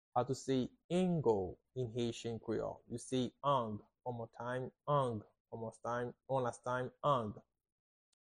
How to say "Angle" in Haitian Creole - "Ang" pronunciation by a native Haitian Creole tutor
“Ang” Pronunciation in Haitian Creole by a native Haitian can be heard in the audio here or in the video below:
How-to-say-Angle-in-Haitian-Creole-Ang-pronunciation-by-a-native-Haitian-Creole-tutor.mp3